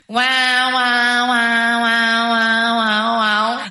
Sirene